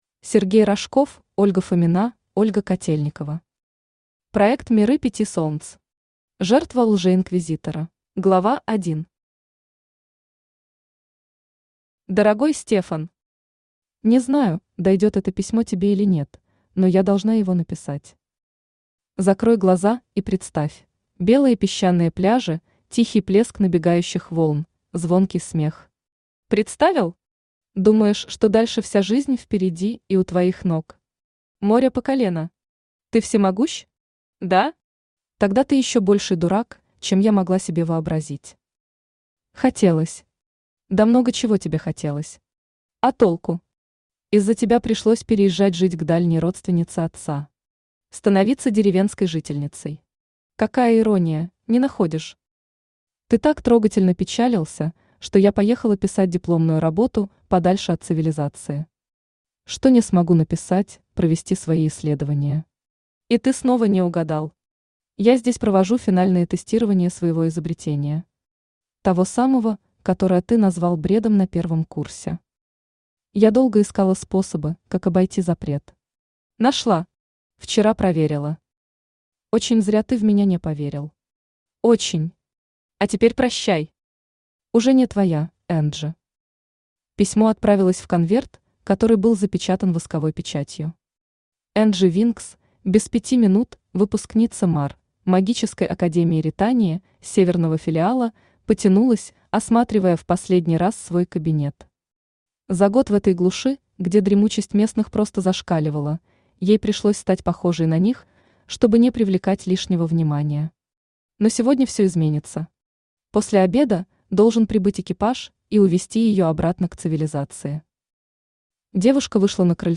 Аудиокнига Проект «Миры пяти солнц». Жертва лжеинквизитора | Библиотека аудиокниг
Жертва лжеинквизитора Автор Ольга Фомина Читает аудиокнигу Авточтец ЛитРес.